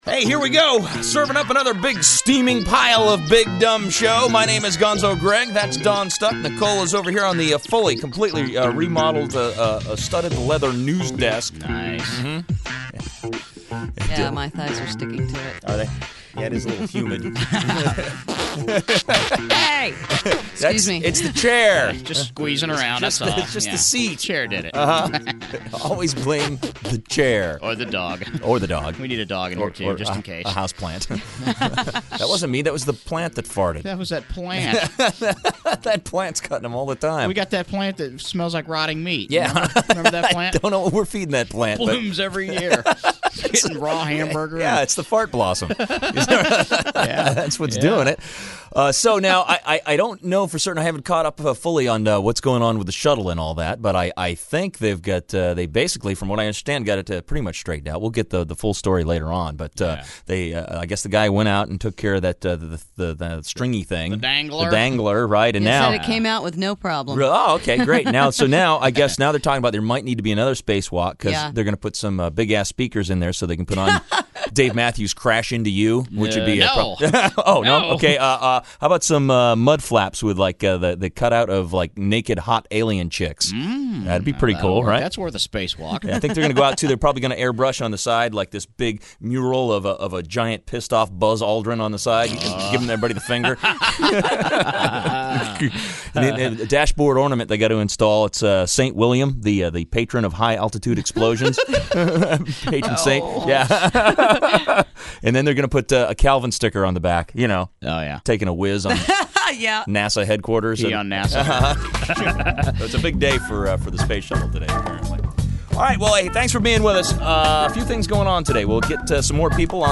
“Warts N’ All” Scoped Airchecks: The Final Week In Indianapolis: